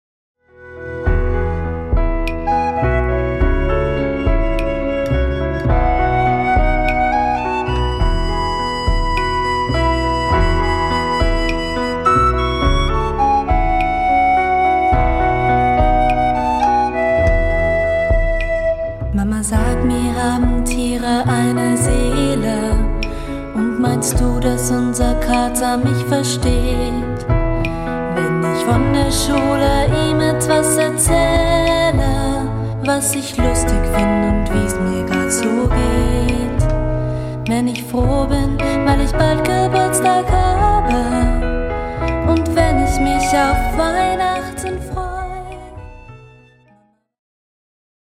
guitar, bass, ukulele, vocals, arrangements
viola da gamba, bass
akkordion, vocals